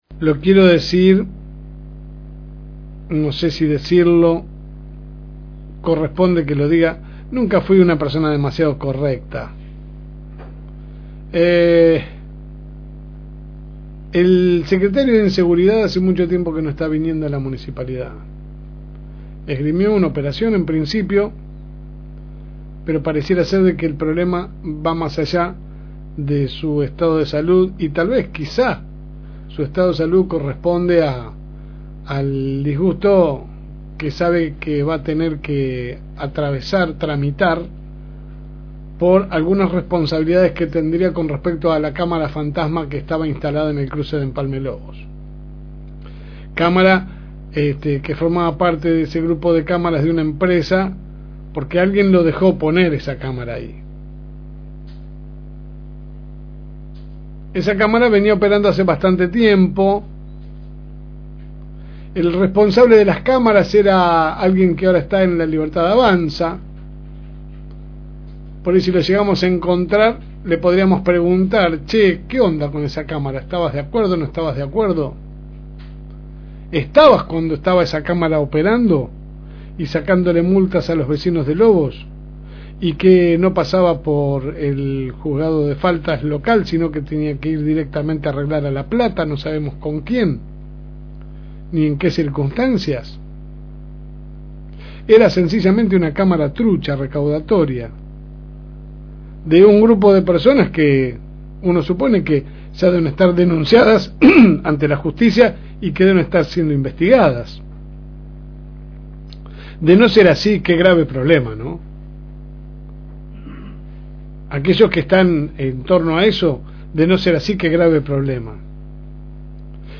editorial